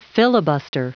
Prononciation du mot filibuster en anglais (fichier audio)
Prononciation du mot : filibuster